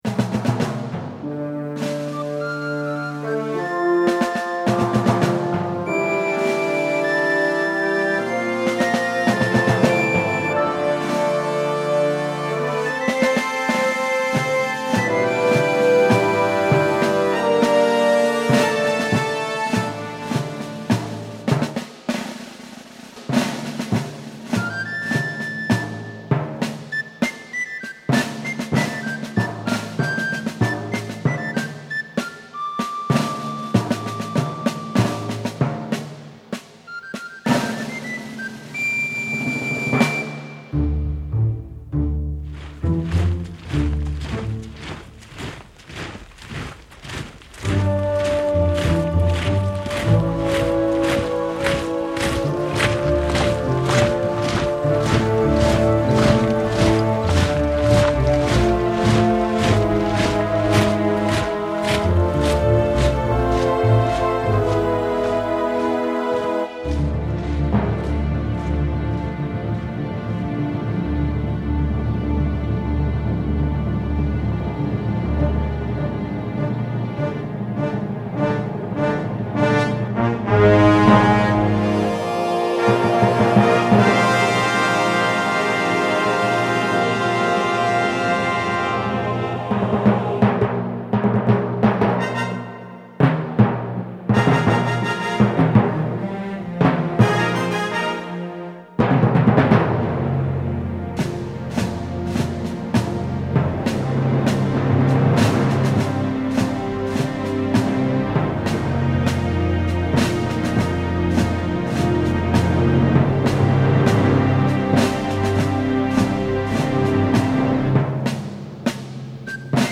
Cine bélico
banda sonora
flauta
melodía